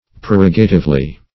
prerogatively - definition of prerogatively - synonyms, pronunciation, spelling from Free Dictionary Search Result for " prerogatively" : The Collaborative International Dictionary of English v.0.48: Prerogatively \Pre*rog"a*tive*ly\, adv.